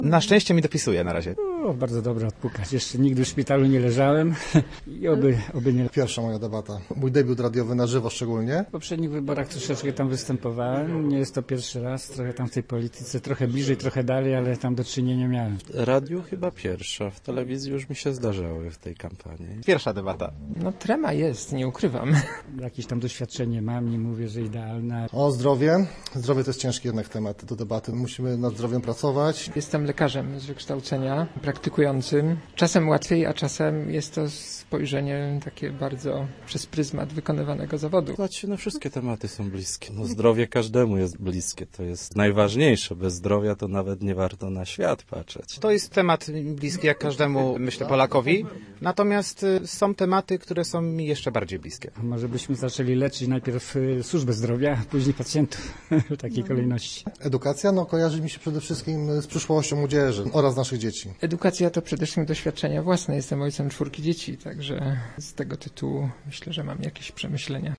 Przedstawiciele pięciu komitetów wyborczych dyskutowali we wtorek na naszej antenie o edukacji i służbie zdrowia. To była już czwarta debata przedwyborcza organizowana przez Radio Merkury.